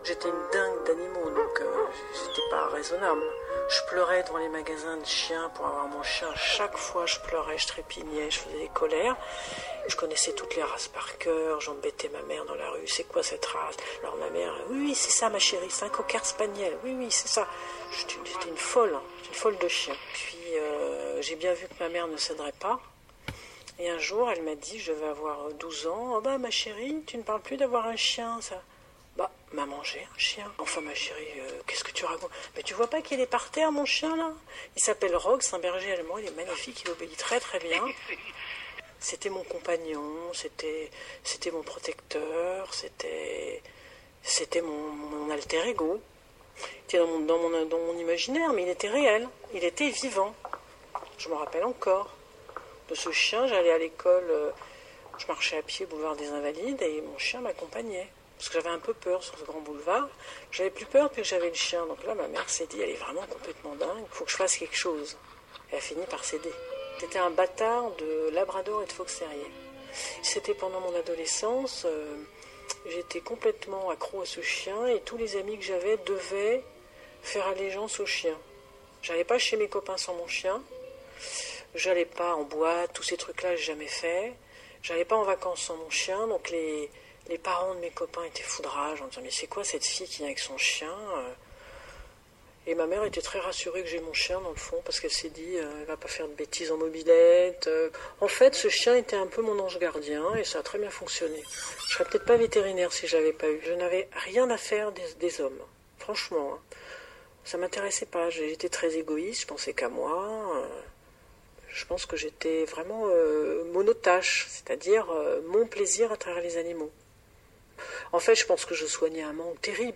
Mais il m’est revenu en entendant cette vétérinaire à la radio. Elle y racontait son enfance et son adolescence, en y disant la place des animaux, les vrais et les imaginaires, avec une sincérité qui m’avait retenue jusqu’au bout.